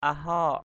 /a-hɔ:ʔ/